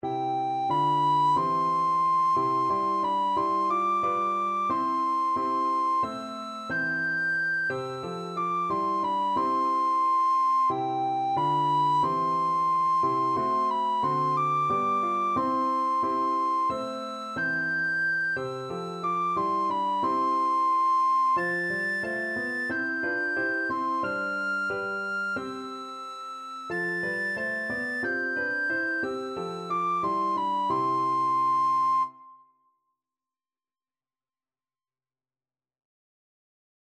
Free Sheet music for Soprano (Descant) Recorder
4/4 (View more 4/4 Music)
C major (Sounding Pitch) (View more C major Music for Recorder )
Recorder  (View more Easy Recorder Music)
Traditional (View more Traditional Recorder Music)